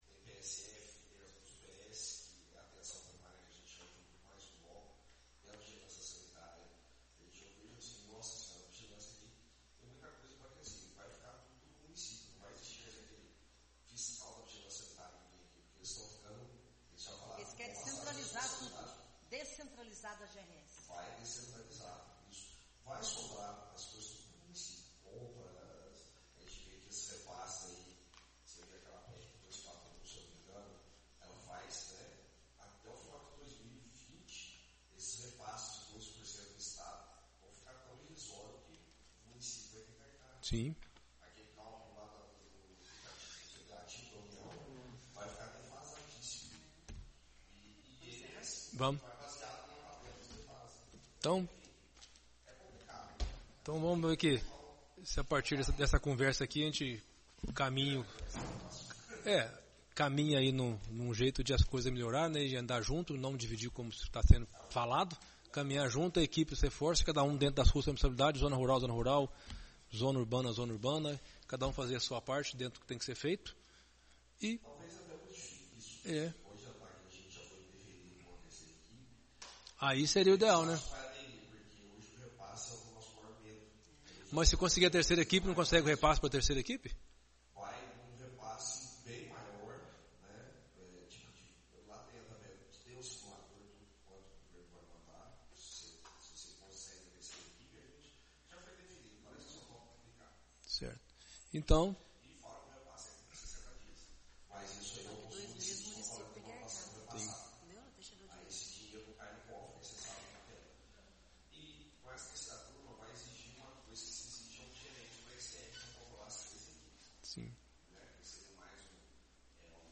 Áudio da 39ª Reunião Ordinária de 2019 e julgamento das contas e esclarecimentos Secretaria de Saúde